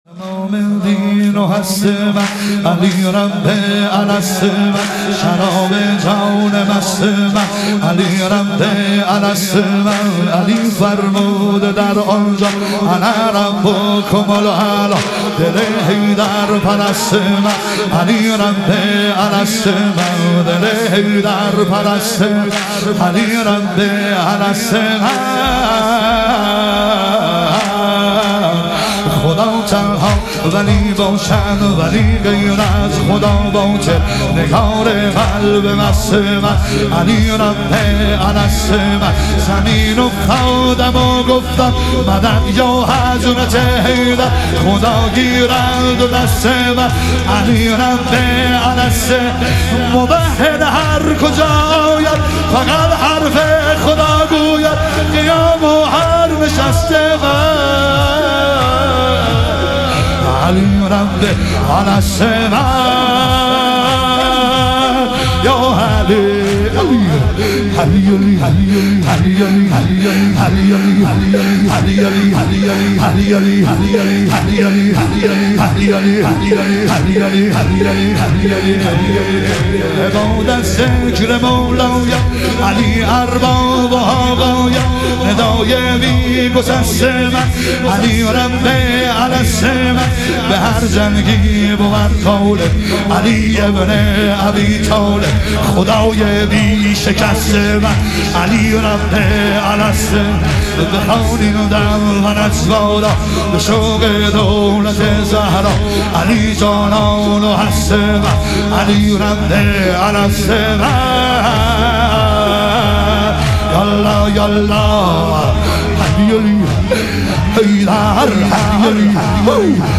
شهادت امام صادق علیه السلام - واحد